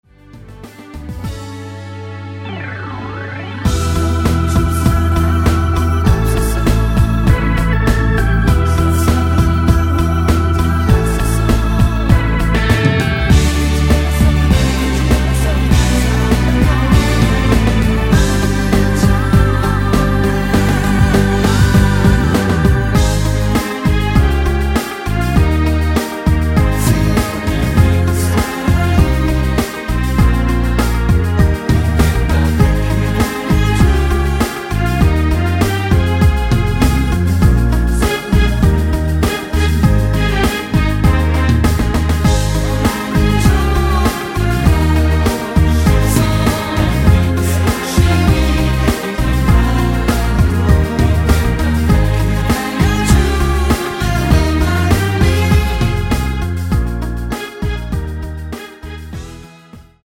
원키 코러스 포함된 MR 입니다.(미리듣기 참조)
앞부분30초, 뒷부분30초씩 편집해서 올려 드리고 있습니다.